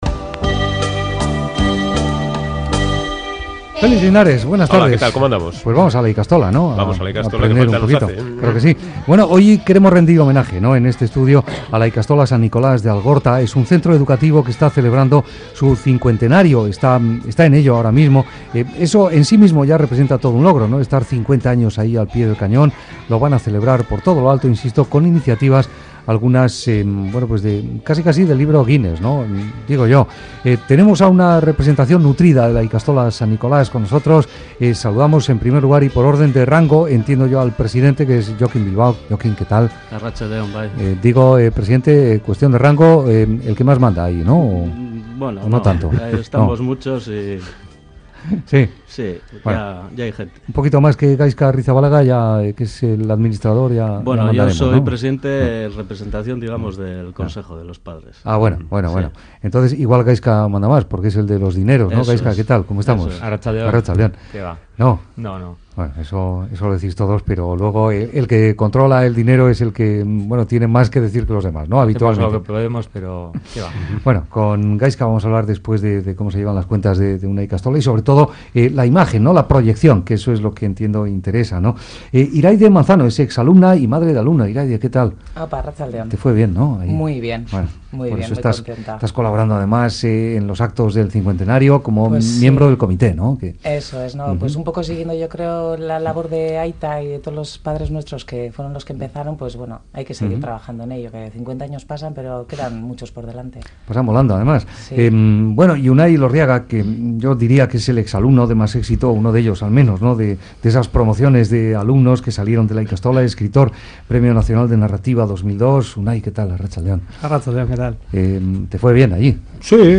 Un nutrido grupo en representación de la ikastola, entre ellos su exalumno Unai Elorriaga, visita Graffiti para repasar su trayectoria.